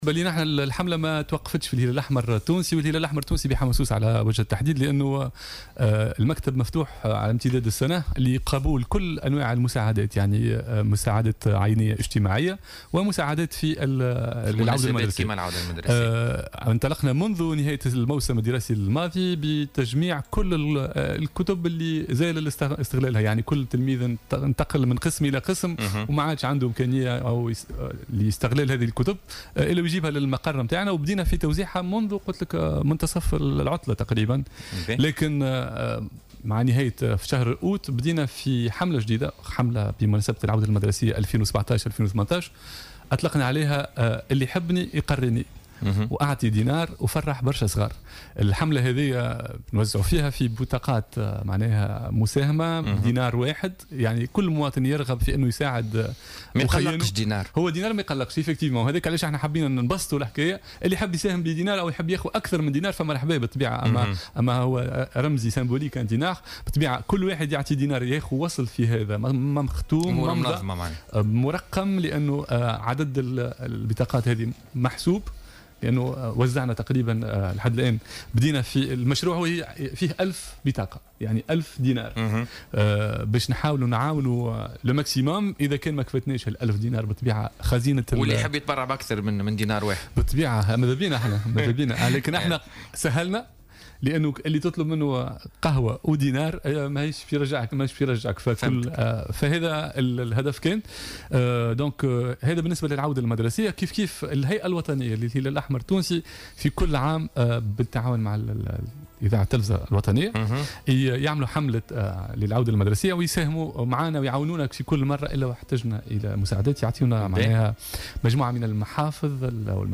lors de son passage sur les ondes de Jawhara Fm, dans le cadre de l’émission Politica